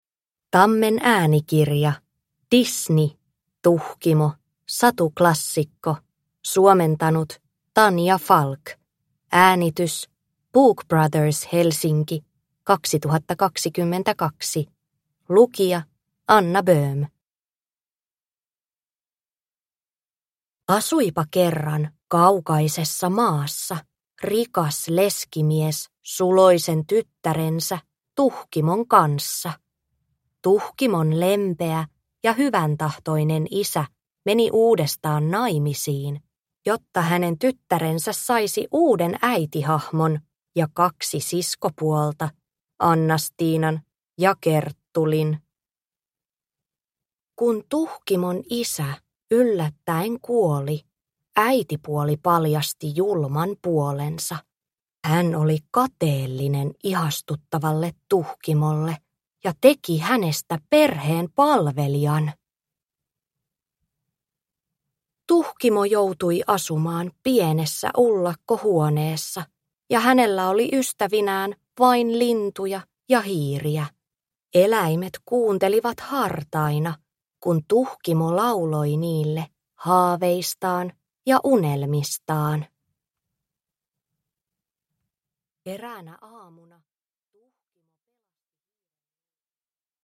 Disney. Tuhkimo. Satuklassikot – Ljudbok – Laddas ner